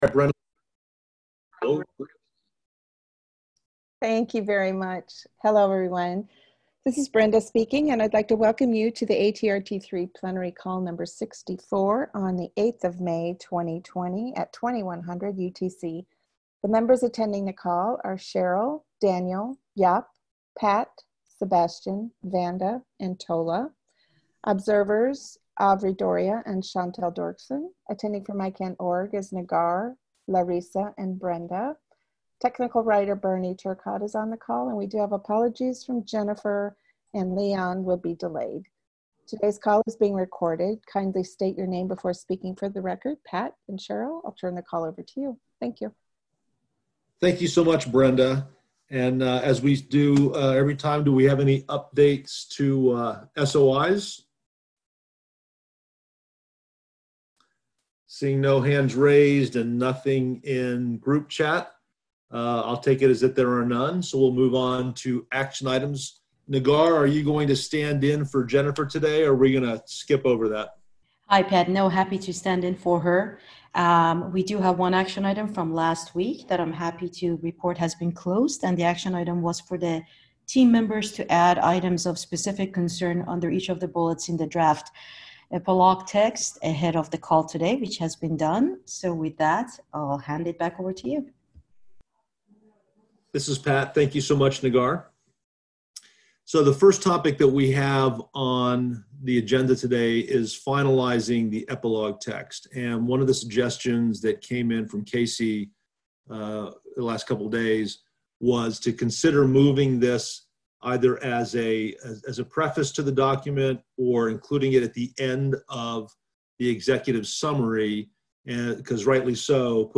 atrt3-plenary-08may20-en.mp3